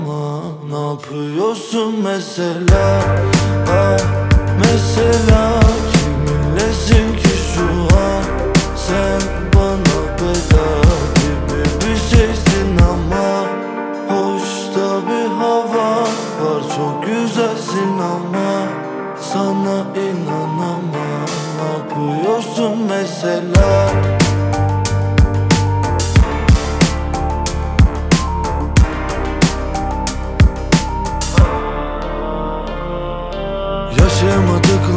Turkish Hip-Hop Rap
Жанр: Хип-Хоп / Рэп